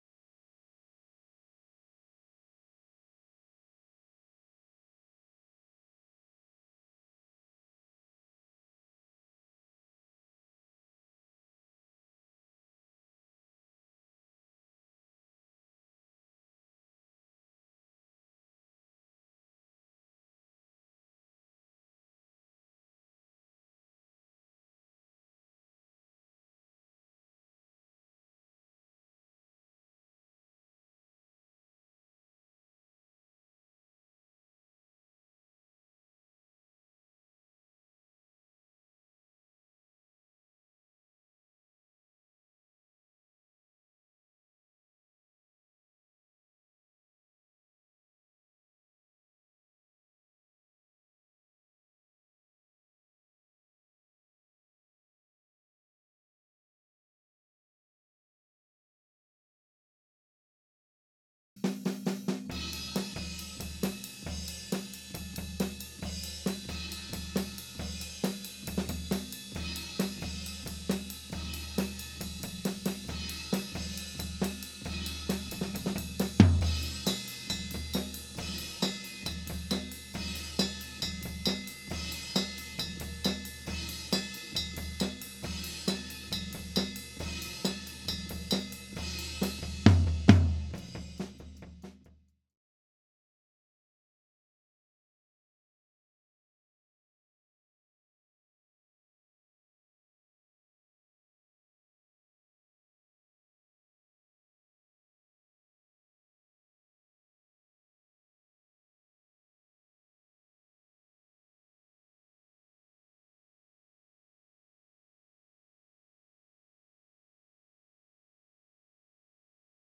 Low Tom.wav